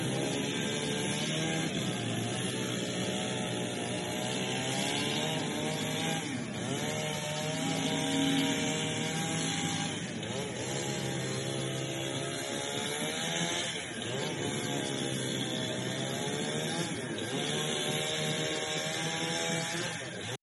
En medio de la noche se escuchan los ruidos de las motosierras.
Sonido de las motosierras, en medio de la noche en el río Manzanares.
motosierra.mp3